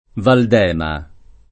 vai all'elenco alfabetico delle voci ingrandisci il carattere 100% rimpicciolisci il carattere stampa invia tramite posta elettronica codividi su Facebook Val d’Ema [ val d % ma o val d $ ma ] o Valdema [id.] top. f. (Tosc.)